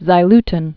(zī-ltn)